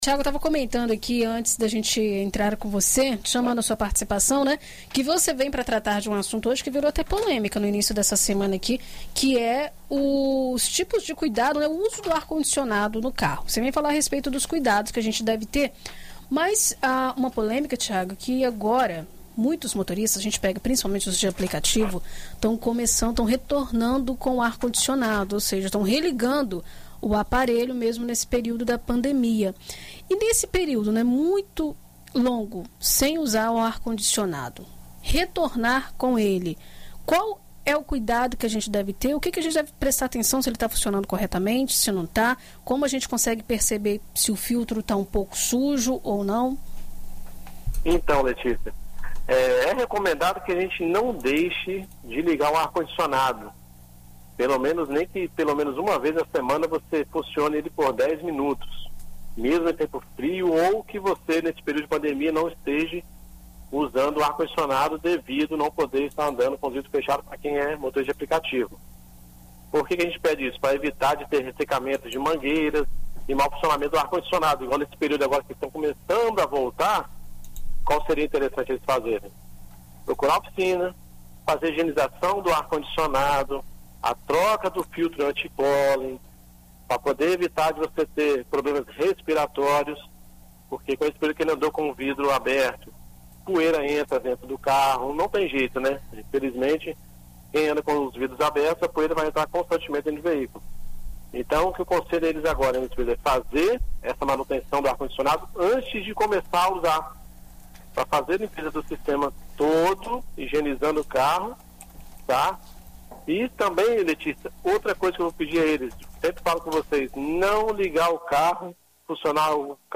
na BandNews FM Espírito Santo